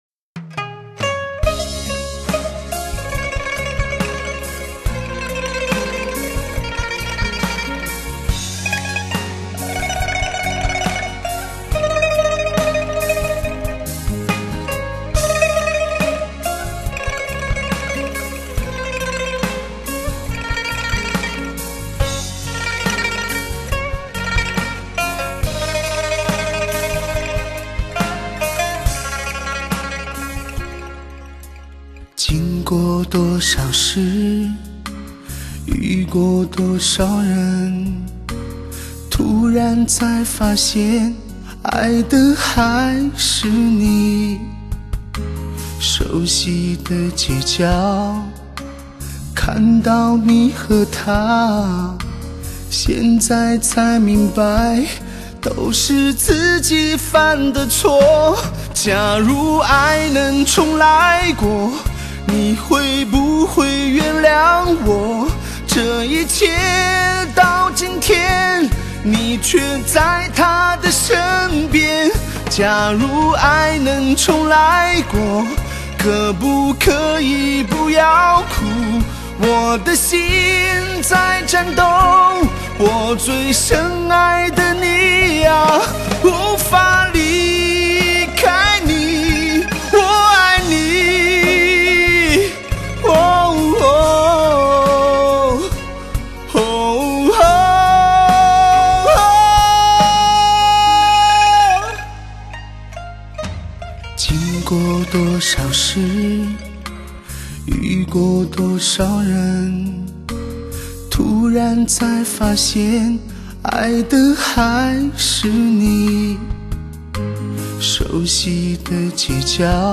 最出色的低频表现  质感  饱满  千锤百炼  一听再听的试音天碟